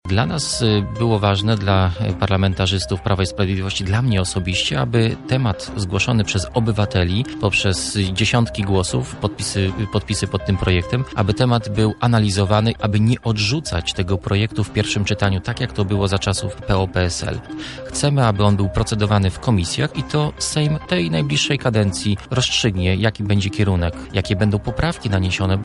Sylwester Tułajew, sekretarz stanu w Ministerstwie Spraw Wewnętrznych i Administracji oraz poseł na Sejm, w Porannej Rozmowie Radia Centrum bronił obywatelskiego projektu nowelizacji Kodeksu Karnego autorstwa inicjatywy „Stop Pedofilii”.
– tak w kontekście dalszego istnienia przedmiotu wychowania do życia w rodzinie mówi Sylwester Tułajew: